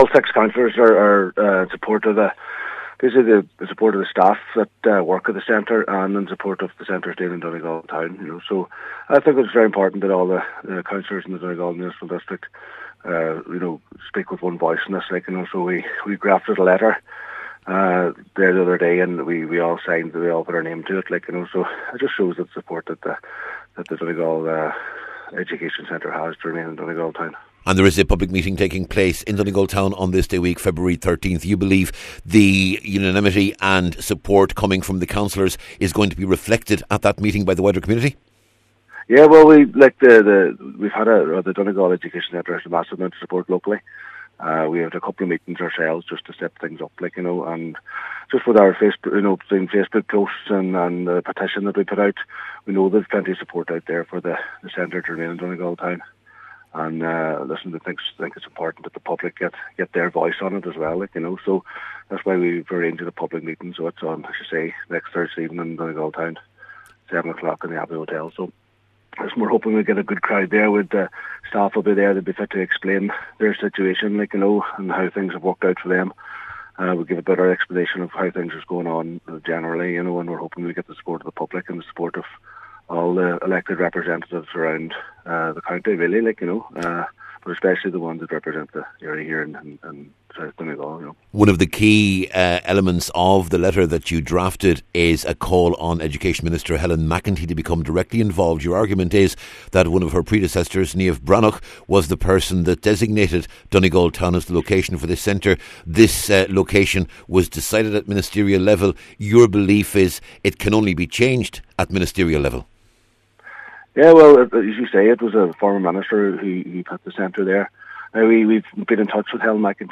Speaking ahead of a public meeting on this day week, he says six local jobs could be lost as a result of the proposal, and given the potential fallout, the final decision should be made by the current minister…………..